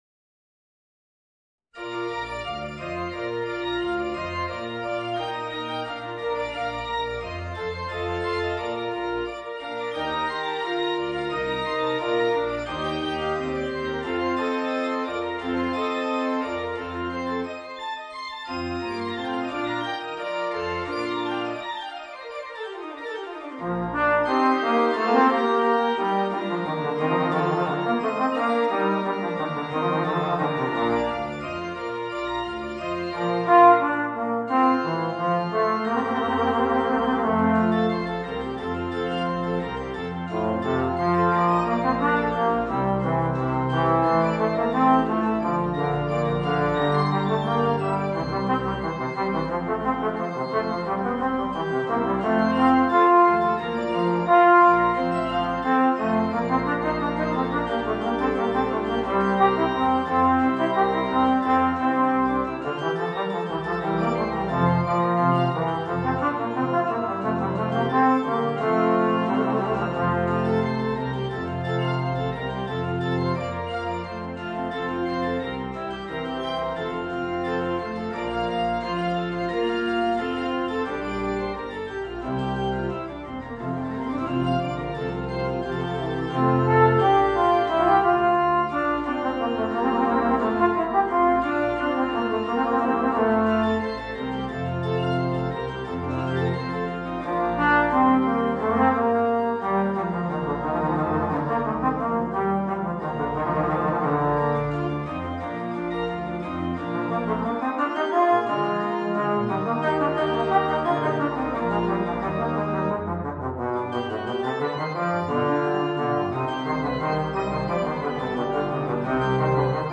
Trombone and Organ